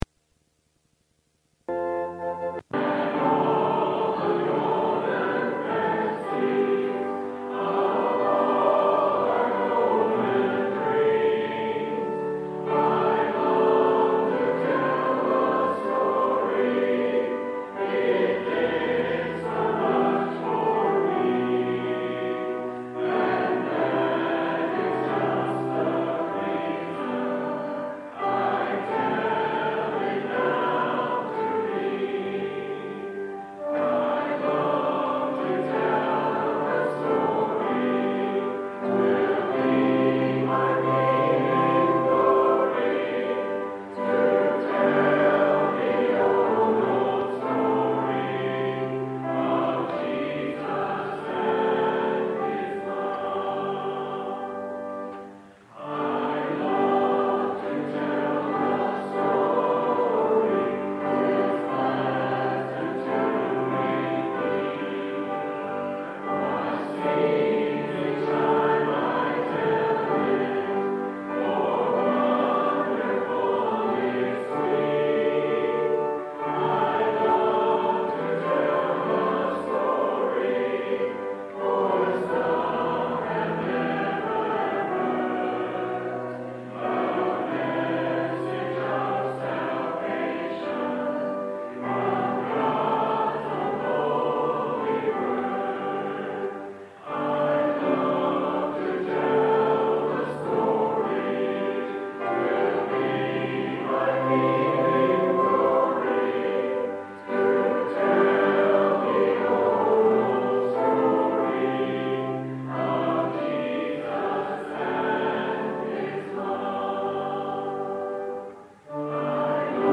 21 Service Type: Sunday Worship Topics: Citizen , Heaven « Don’t Look Back July-28-2019 Renewed Like an Eagle